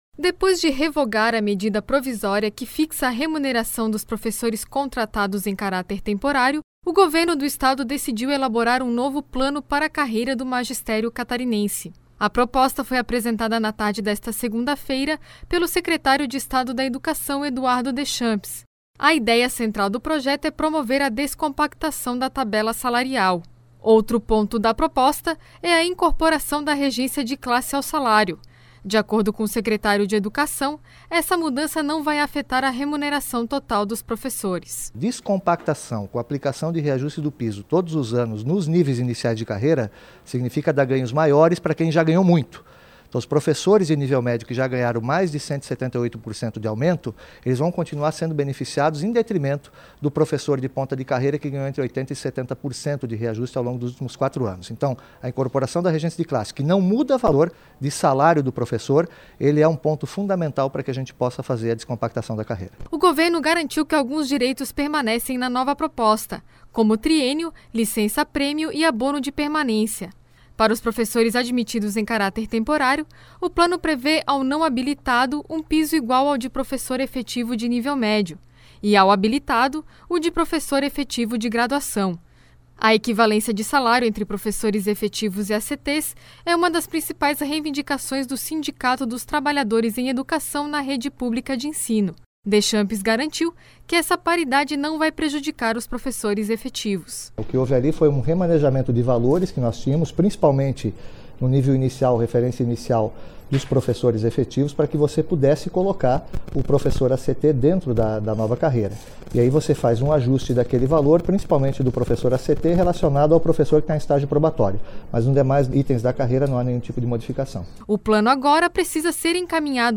Entrevista com: Eduardo Deschamps, secretário de educação de Santa Catarina